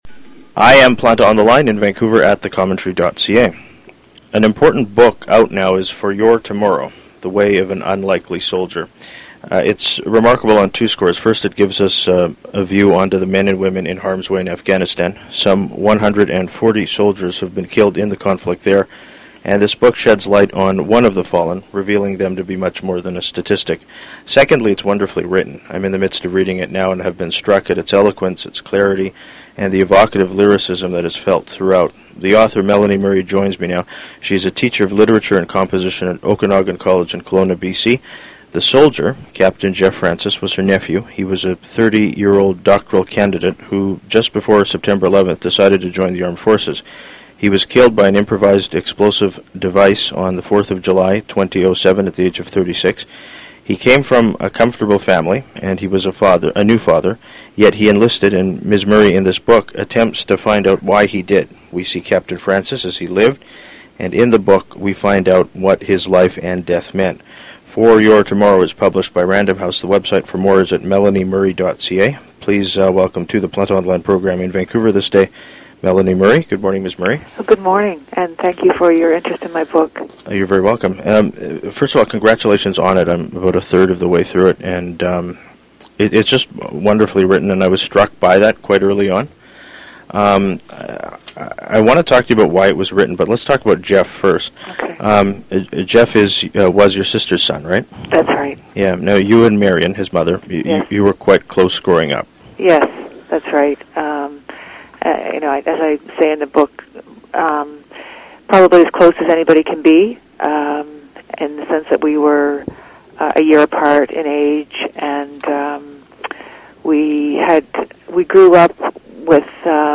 Text of introduction